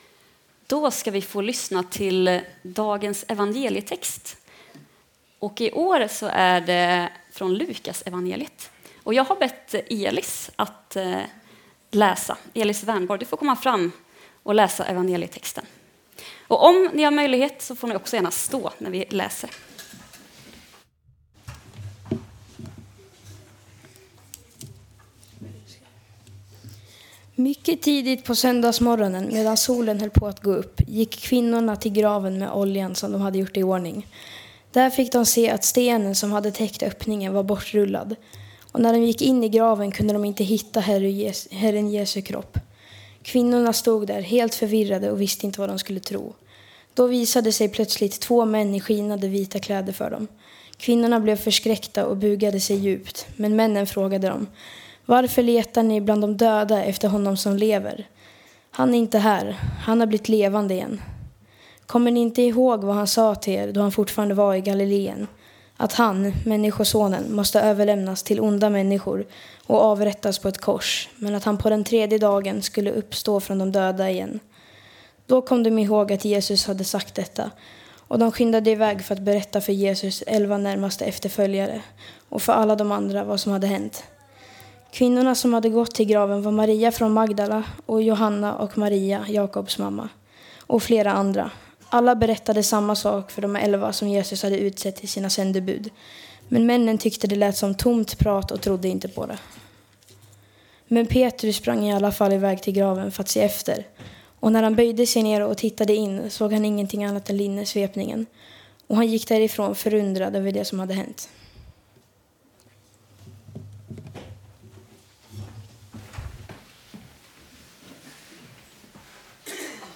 Predikan
Inspelad under gudstjänst i Equmeniakyrkan Väte 2026-04-05.